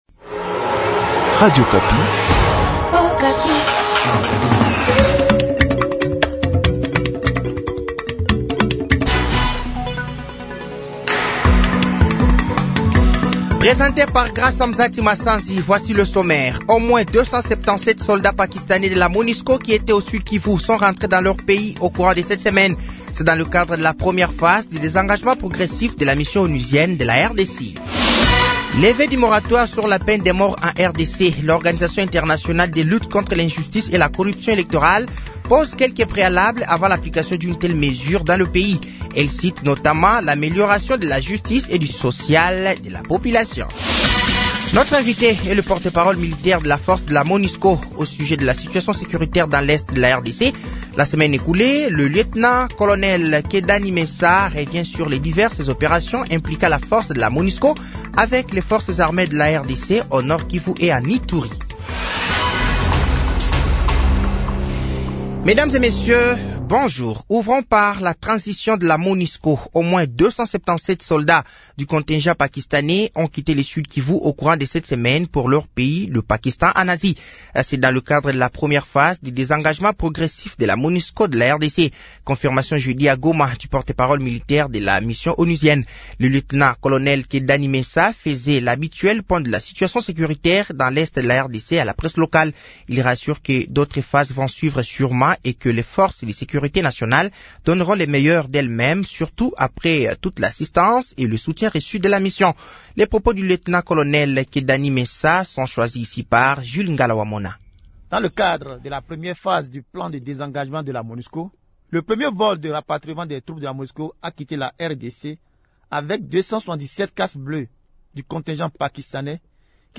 Journal français de 7h de ce samedi 30 mars 2024